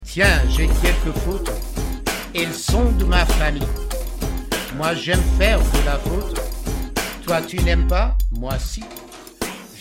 French Raps Song Lyrics and Sound Clip